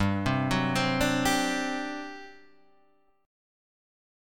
GM7sus4 chord